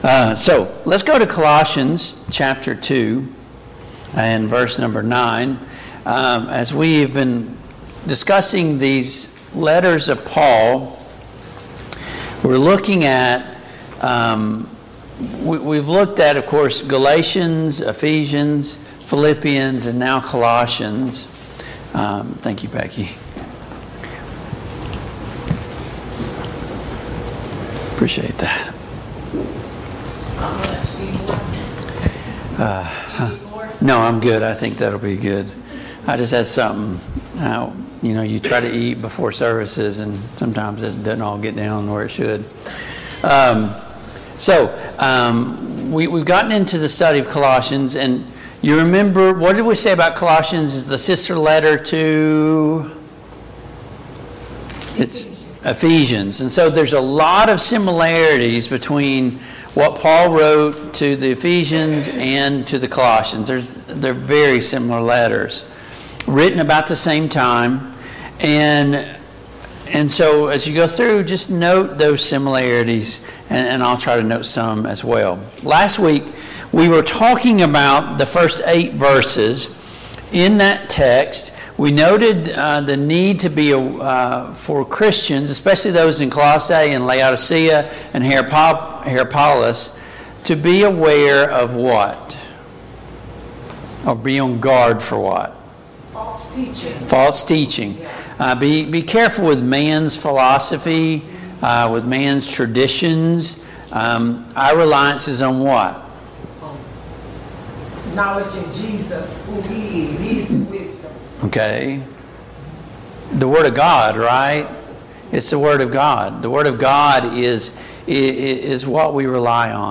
Passage: Colossians 2:9-17 Service Type: Mid-Week Bible Study